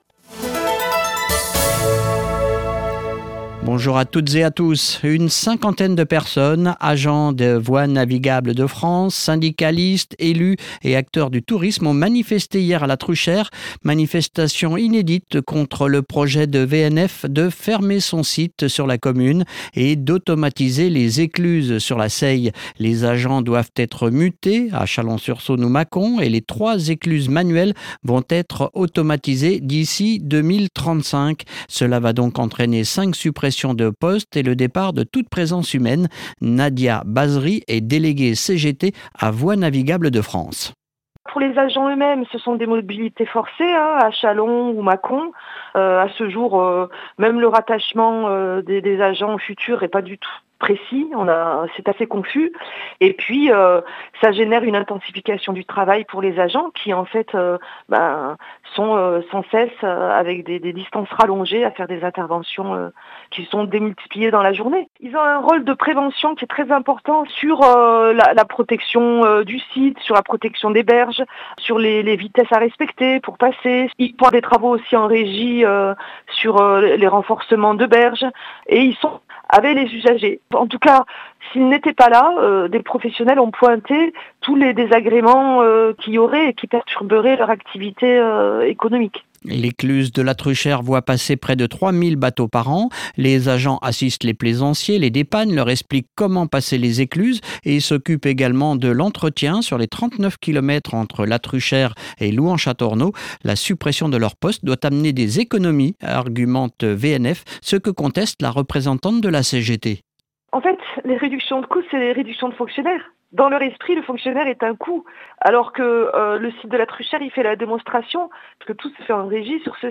Extrait des infos locales de Radio bresse du 5 mars 2026